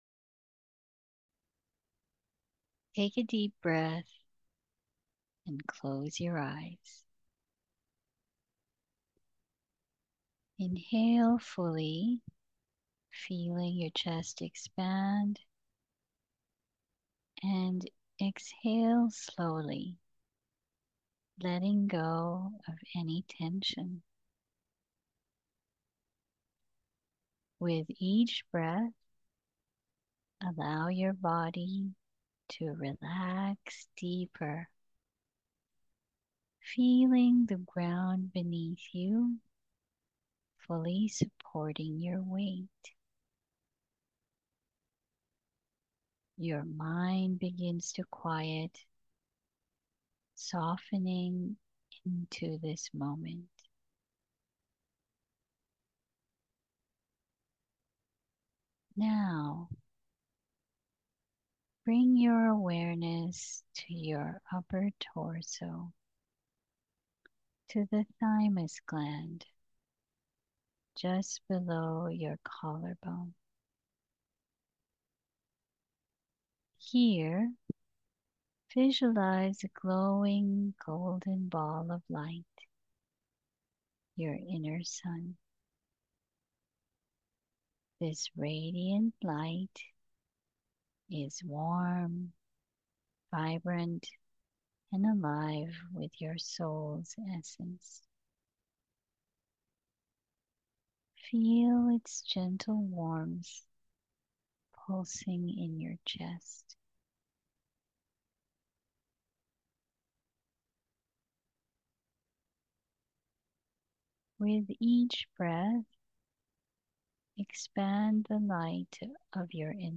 Meditation Audio